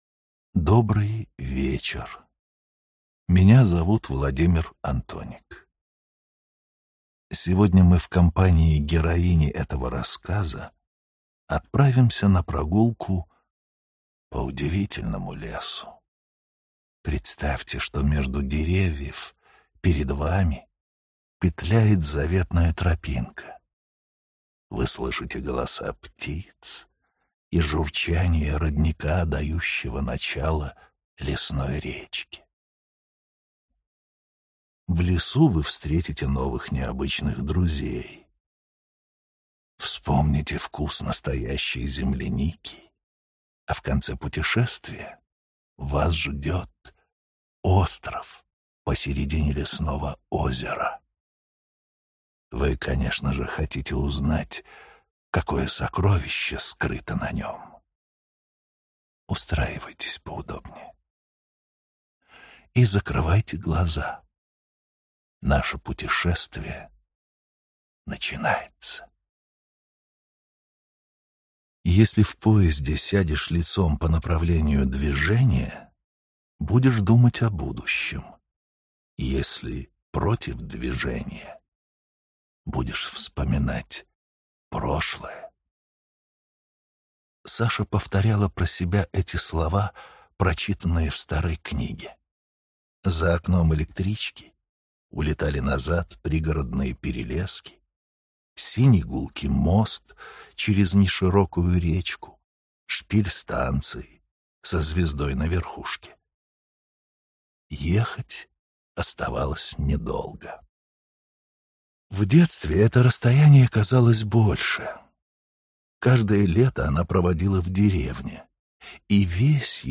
Aудиокнига Лесная Сага Автор Антон Алеев Читает аудиокнигу Владимир Антоник.